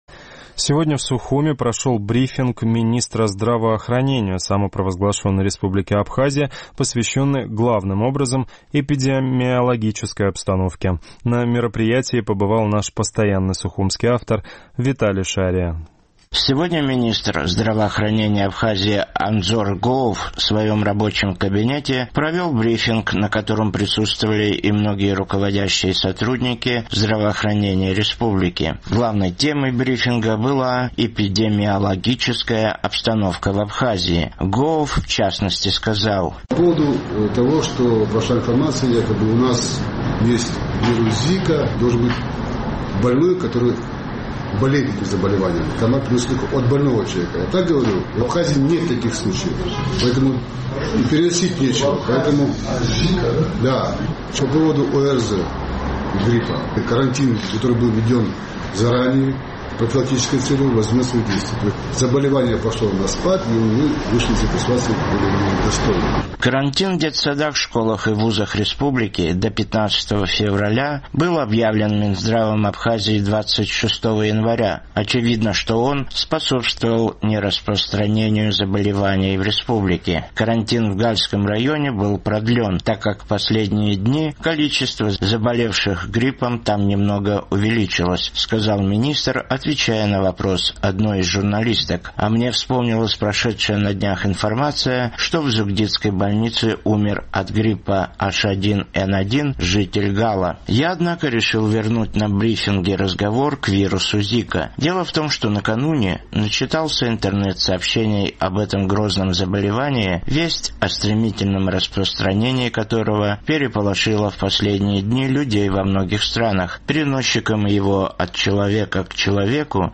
Темой брифинга министра здравоохранения Абхазии Андзора Гоова, которую он провел в своем рабочем кабинете, была эпидемиологическая обстановка в республике.